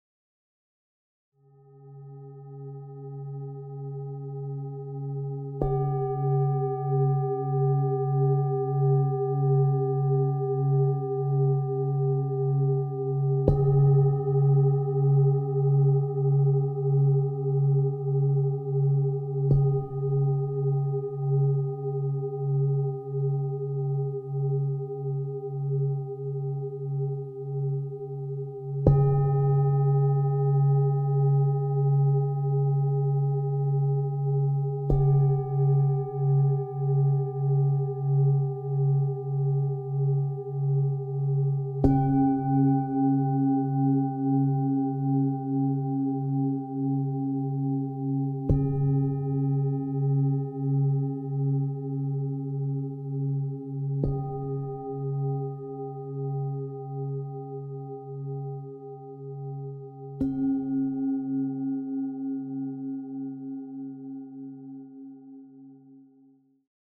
Musik für Meditation mit planetarischen Tönen
eingespielt mit PlanetenKlangschalen und ChakraKlangschalen.
Musik mit Klangschalen und Planetentönen 4.